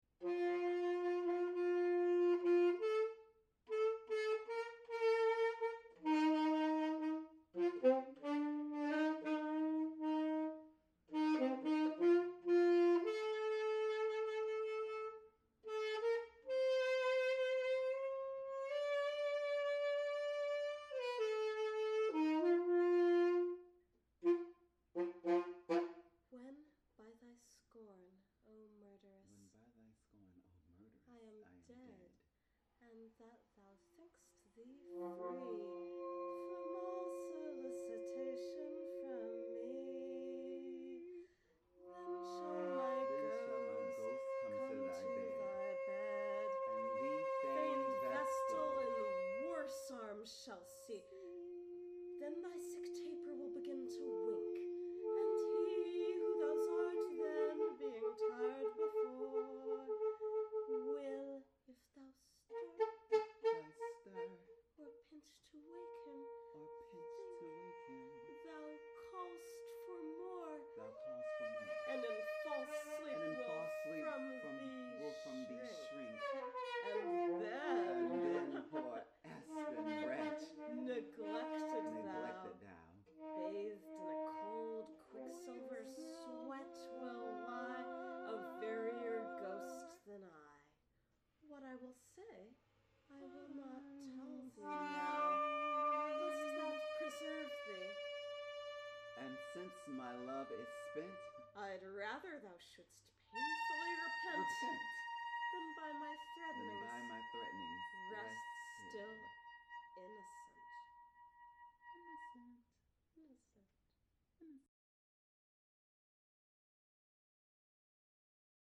Saxophone, two voices:
Burlesque/musical version: saxophone, two voices, singing:
tenor saxophone